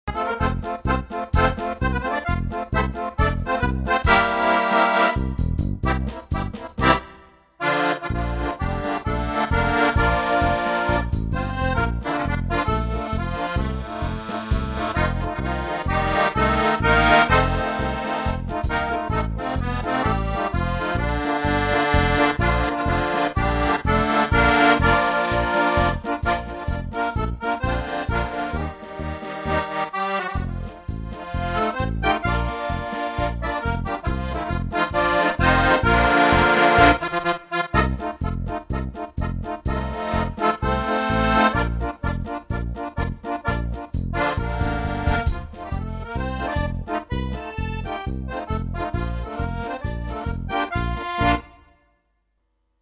Die Aufnahmen stammen noch aus dem Jahr 2012 - aufgenommen auf meinem alten Tyros 2 Keyboard von Yamaha nach dem Motto: